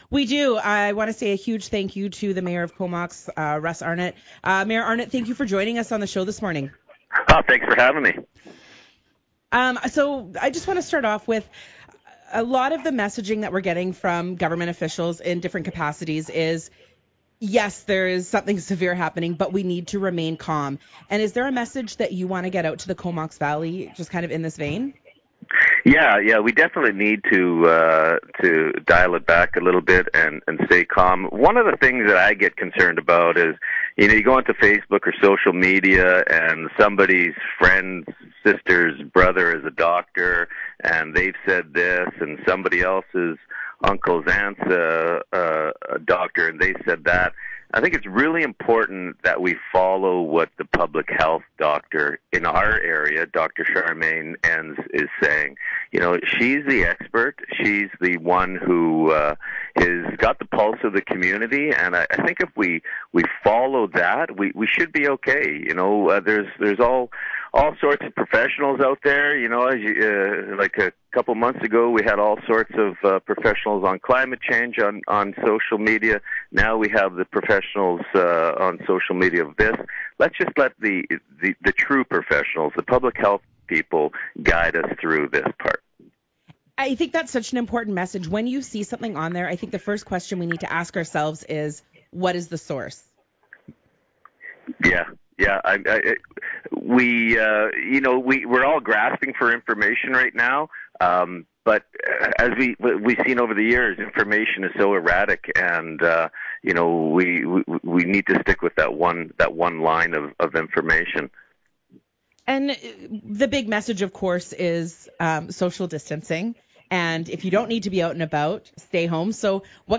That person will be interviewed live during Montana Mornings